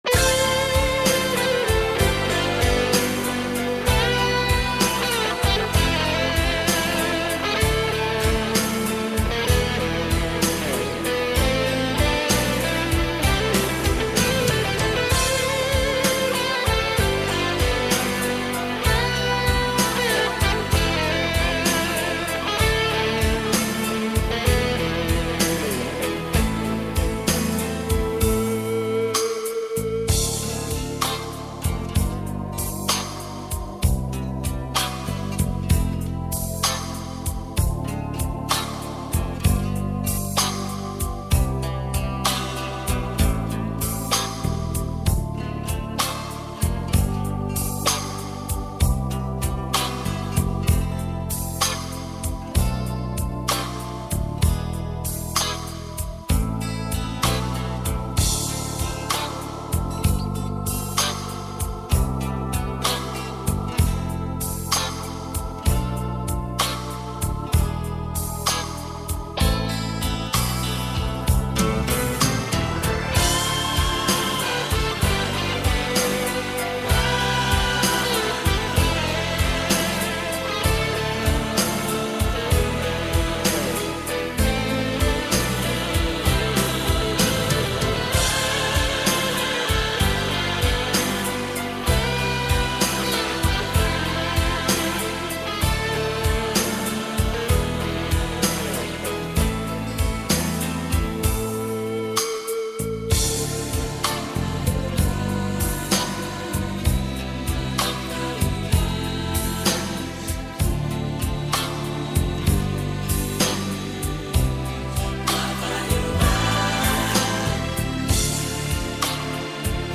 минусовка версия 33942